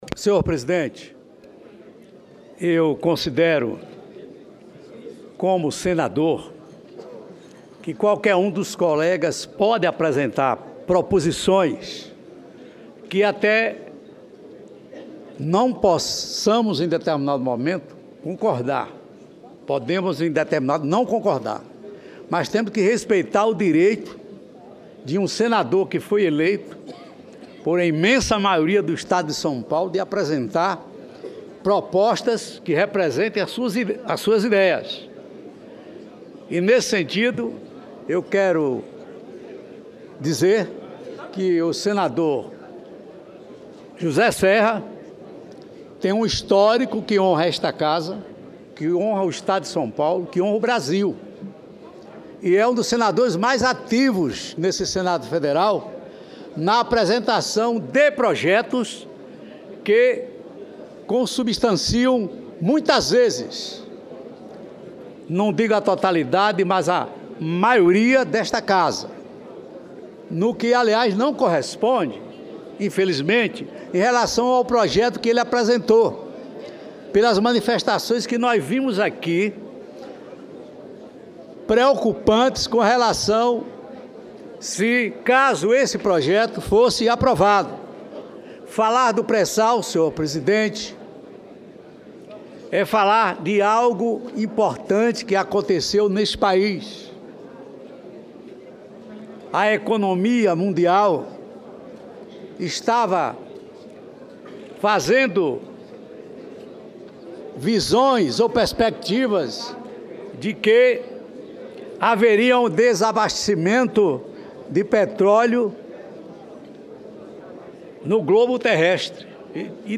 Pronunciamento do senador Antonio Carlos Valadares